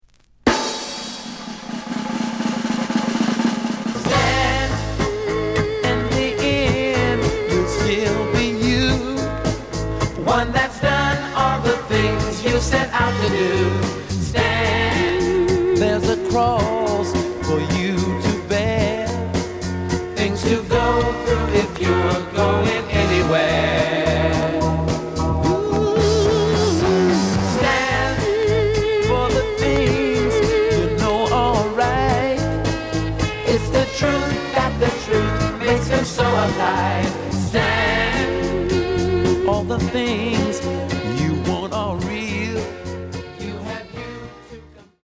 The audio sample is recorded from the actual item.
●Genre: Soul, 60's Soul
Some click noise on B side label due to scratches.